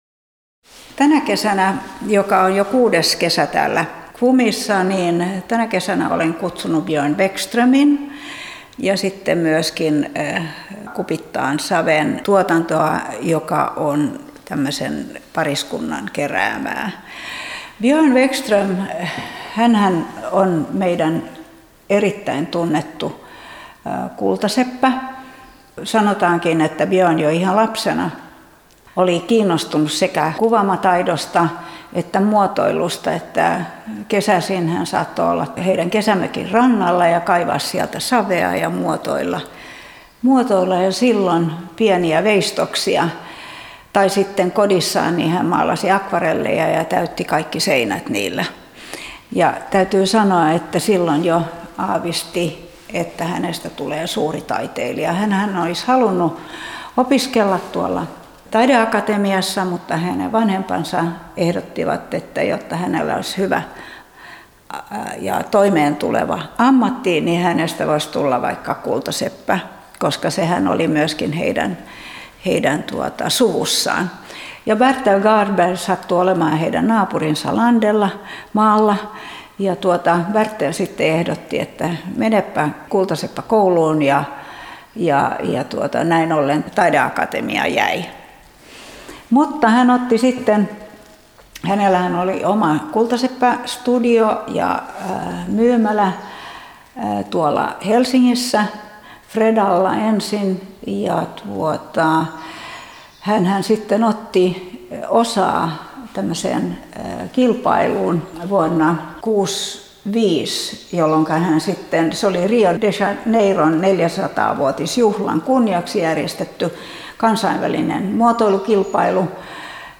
KWUM – kesä 2024 kuraattorikierros
KWUM-kuraattorikierros.mp3